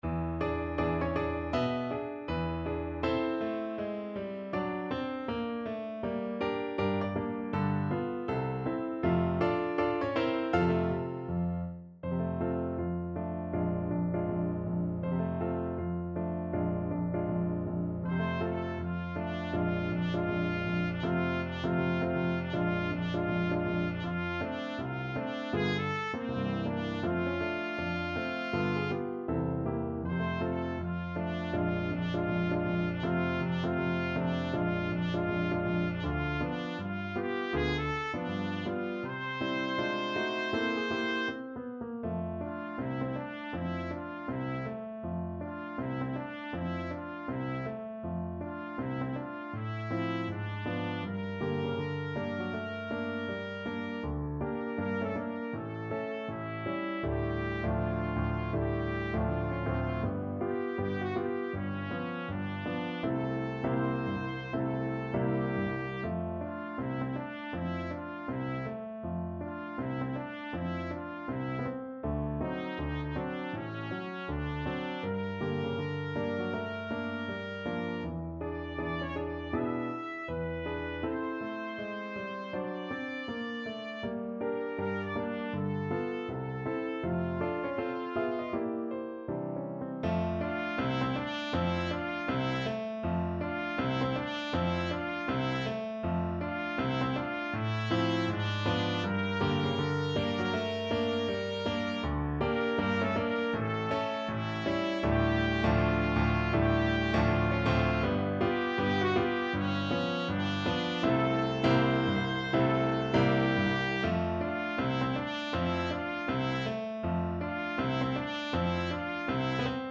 2/2 (View more 2/2 Music)
~ = 160 Moderato
C5-E6
Jazz (View more Jazz Trumpet Music)